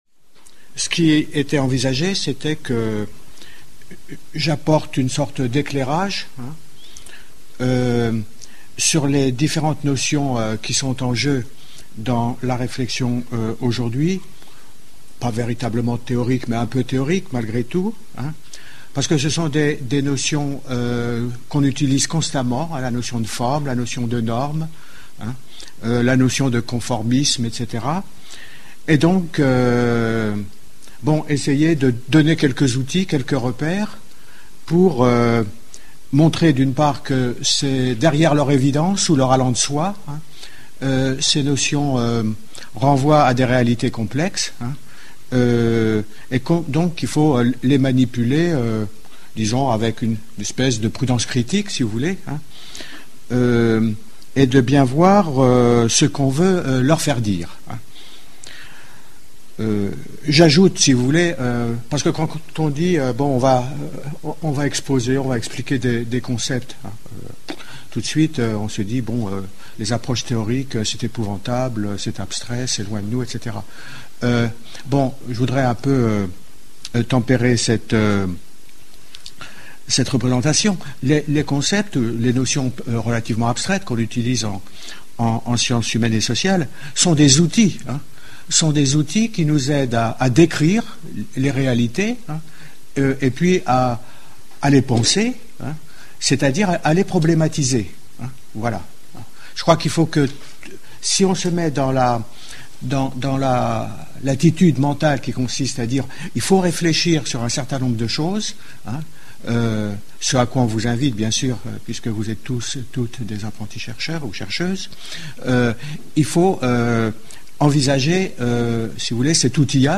L'exposé